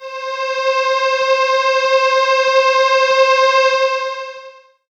37j01pad1-c.wav